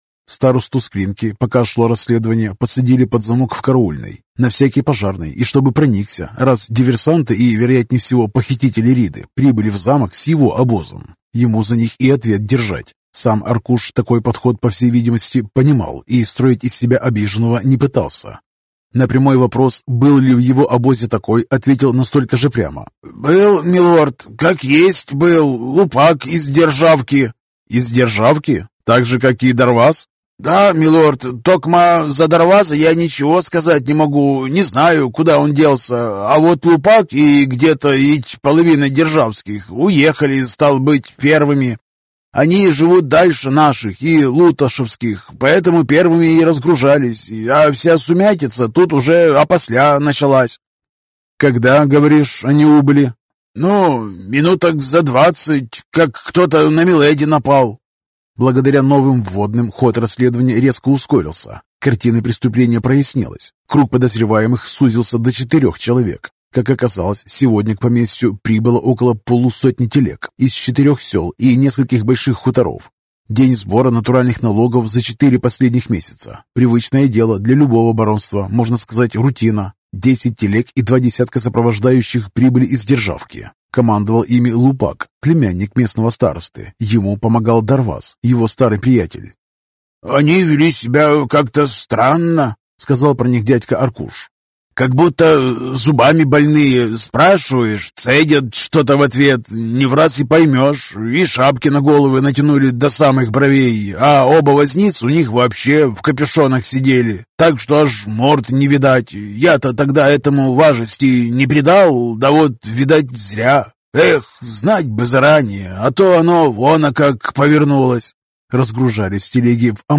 Аудиокнига Враг с планеты Земля (том 2) | Библиотека аудиокниг